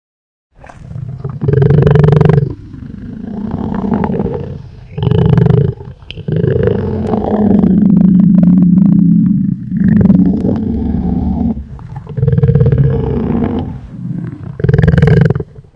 Deep Tiger Growl Rumble Predator Sfx Téléchargement d'Effet Sonore